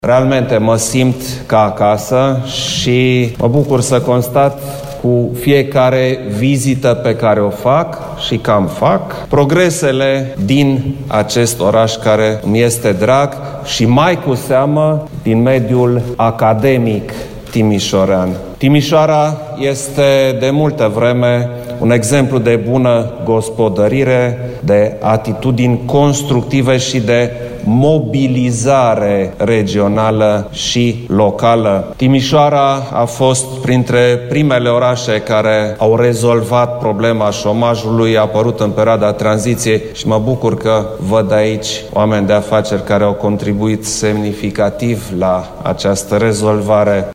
Timişoara face progrese în toate domeniile, de la un an la altul, a declarat preşedintele României, prezent la aniversarea a 75 de ani a Universităţii de Vest.
Klaus-Iohannis-despre-Timisoara.mp3